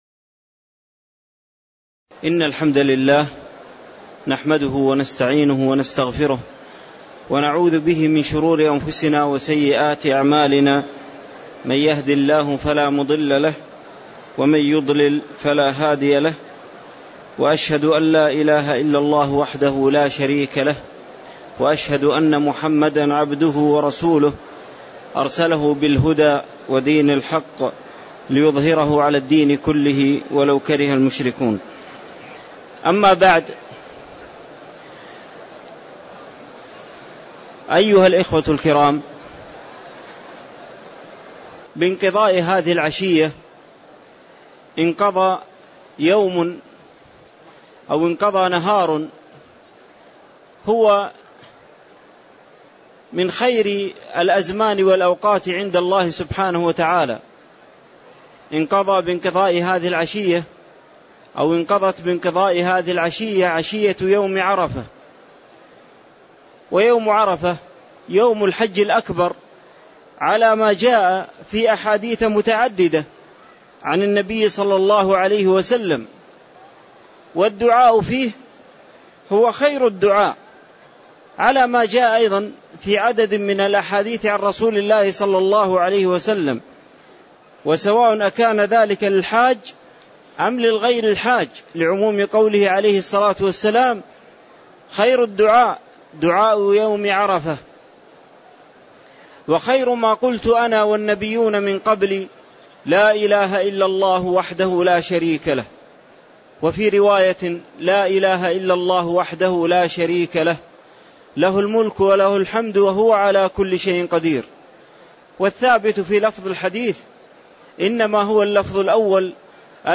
تاريخ النشر ١٠ ذو الحجة ١٤٣٧ هـ المكان: المسجد النبوي الشيخ